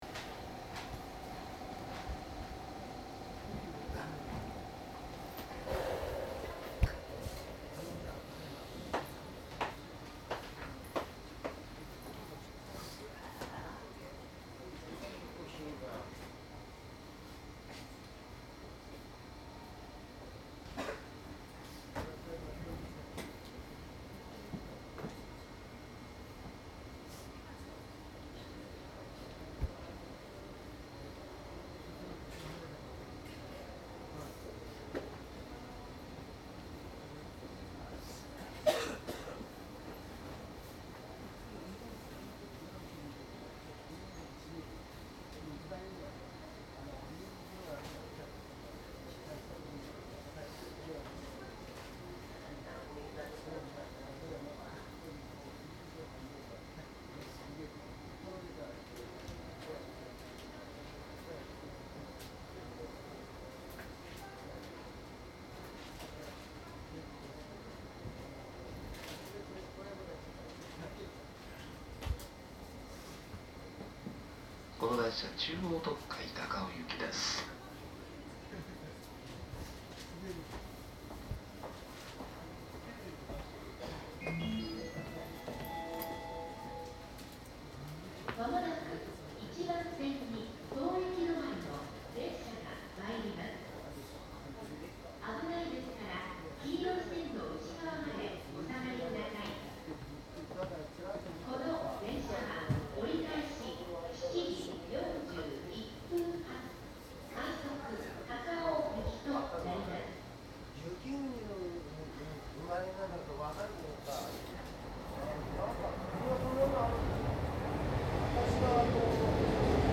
中央線 201系 特快 走行音 CD
こちらは特快の録音でしたが中野～国分寺でお客様の声が大きく入ったため、その区間が青梅特快で録音しています。
マスター音源はデジタル44.1kHz16ビット（マイクＥＣＭ959）で、これを編集ソフトでＣＤに焼いたものです。